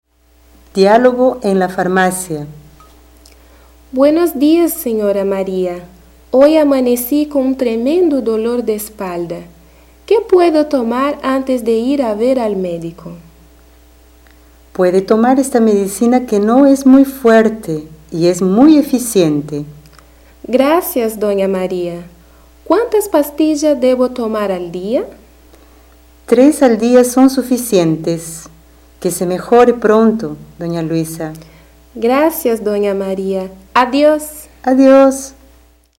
Description: Áudio do livro didático Língua Espanhola I, de 2008. Diálogo com palavras referentes as partes do corpo.